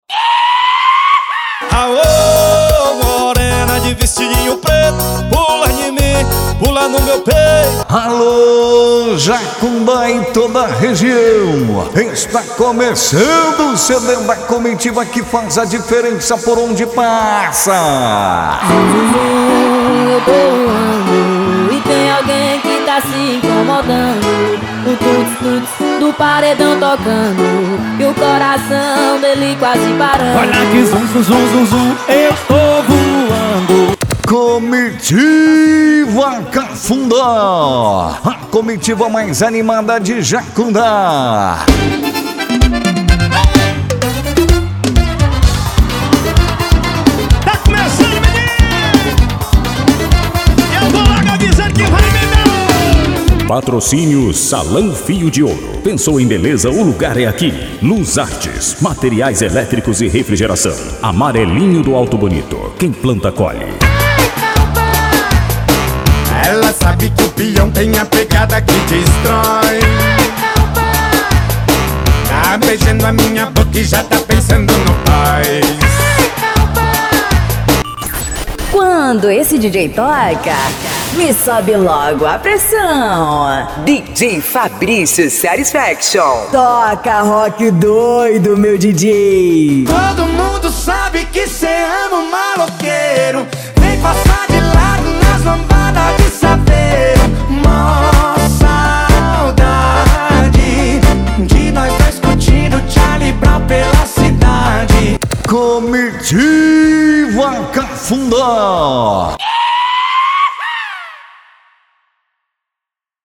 Arrocha
Funk
SERTANEJO
Sertanejo Universitario
Sets Mixados